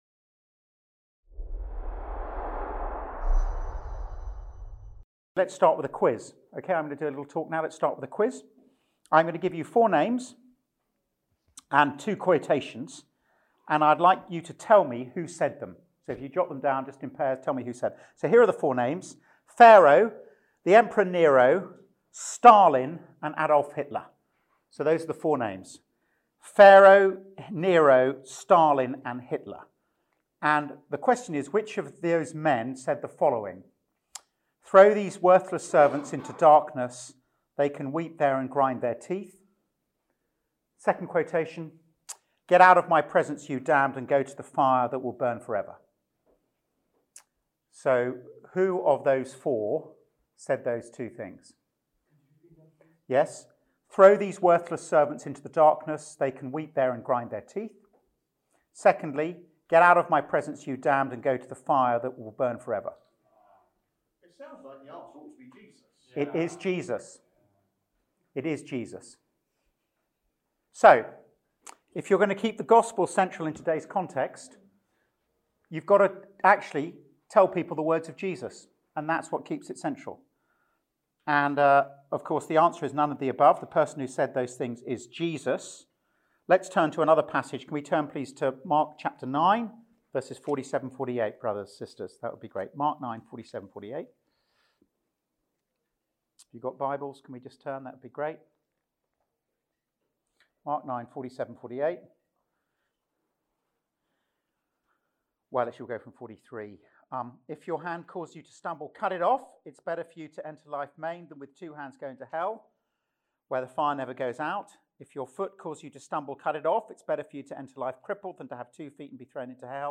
Event: ELF Church Revitalisation Network